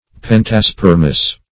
Search Result for " pentaspermous" : The Collaborative International Dictionary of English v.0.48: Pentaspermous \Pen`ta*sper"mous\, a. [Penta- + Gr.